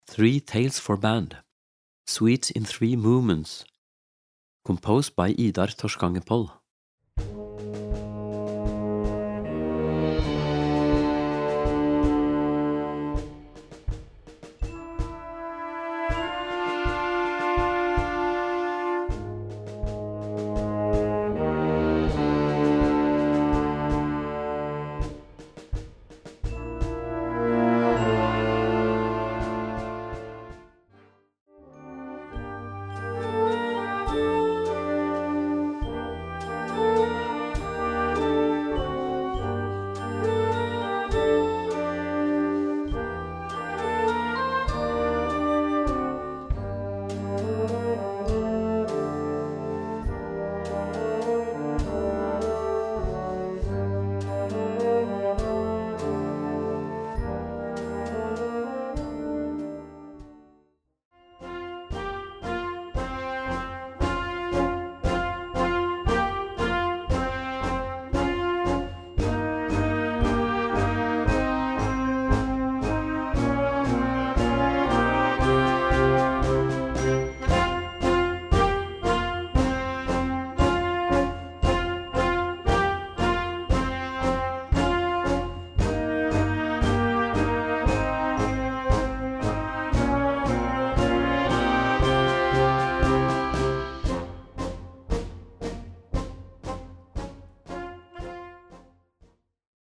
Gattung: Jugendwerk
1:24 Minuten Besetzung: Blasorchester PDF